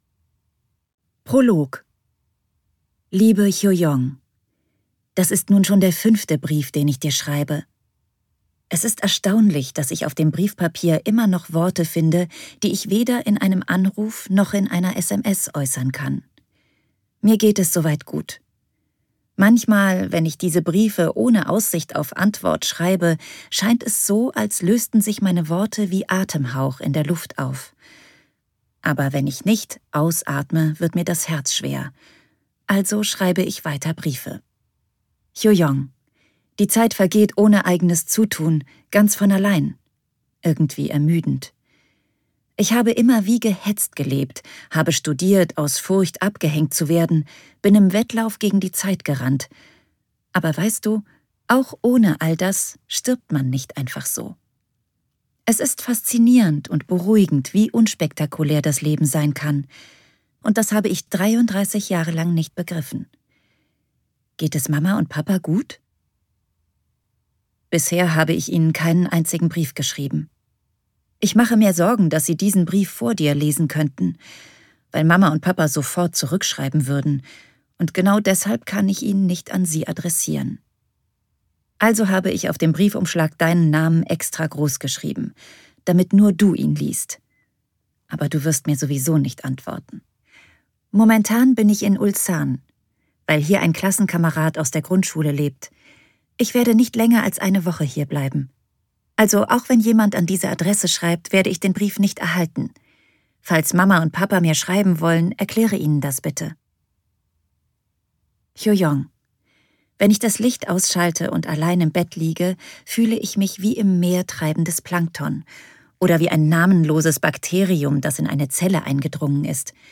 Ein Hörbuch für alle, die schönes Briefpapier lieben und die nach Entschleunigung suchen.